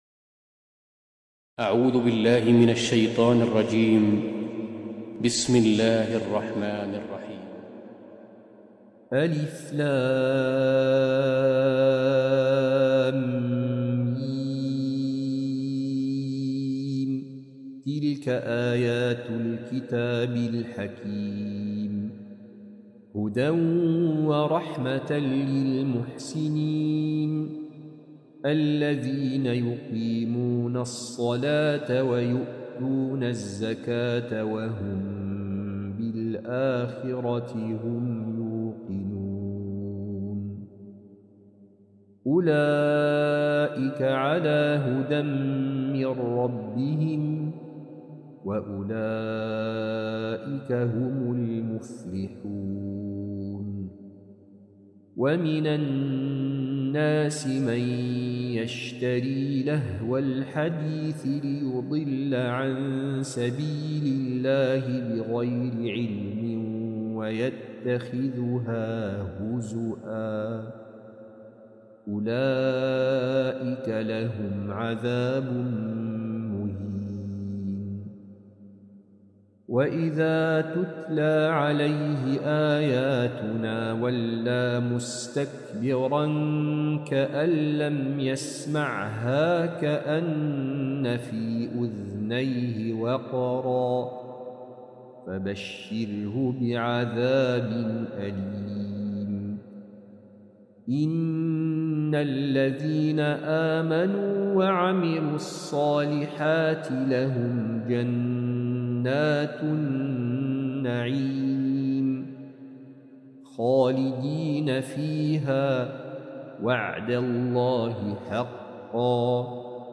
القرآن الكريم - تلاوة وقراءة بصوت أفضل القراء
الاستماع للقرآن الكريم بصوت القارئ